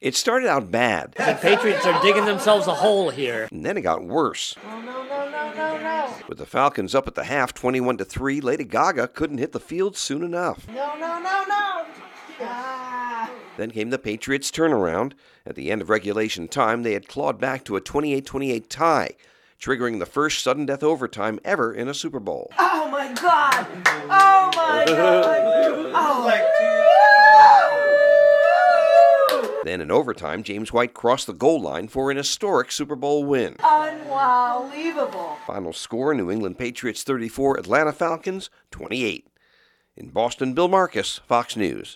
WAS AT A PARTY WATCHING FANS: